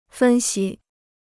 分析 (fēn xī): to analyze; analysis.